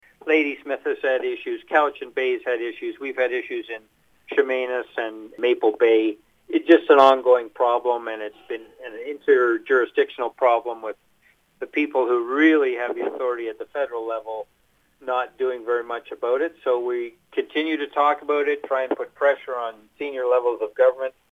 Board Chair Jon Lefebure says they have already done a tour of the new Campbell River hospital as part of the convention, in hopes of seeing something similar come to the Cowichan Valley in the near future. He is also looking forward to conversation on derelict vessels.